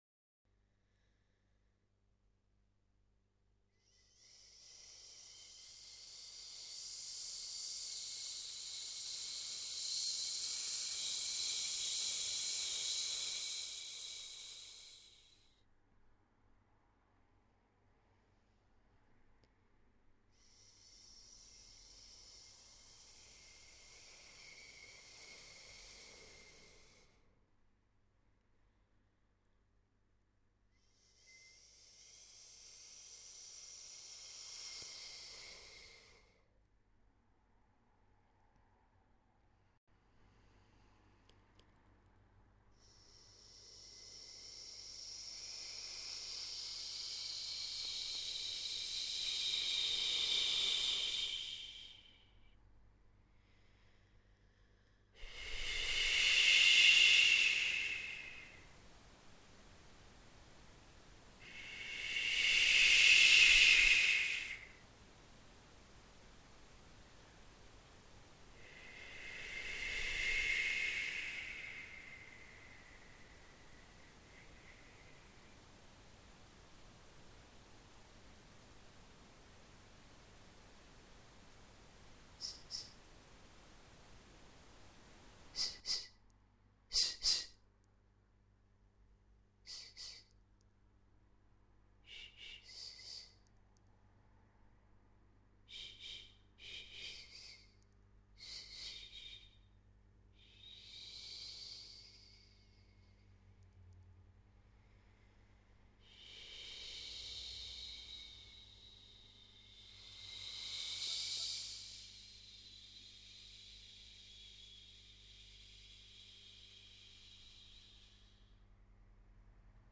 Second improvisation for the white noise and feedback patch, via standalone (tap tempo not working)